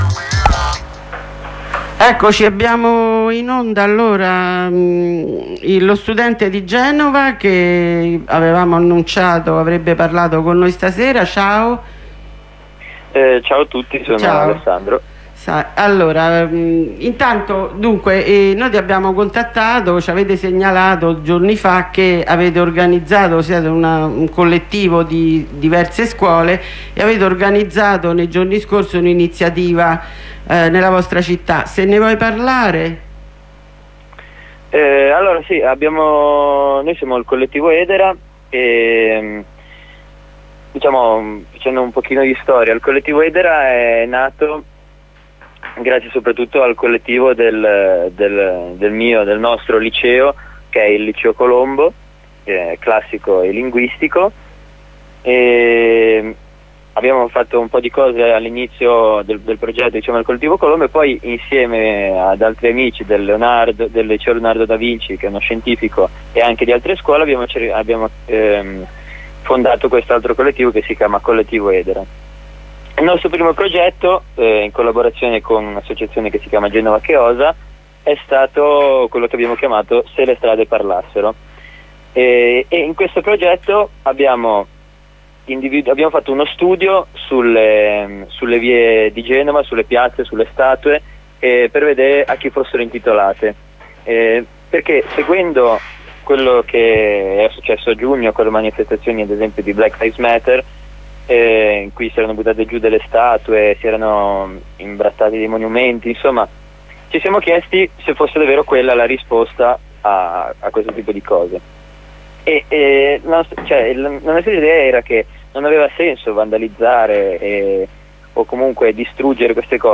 Corrispondenza telefonica con il CSOA Terra di Nessuno (Genova)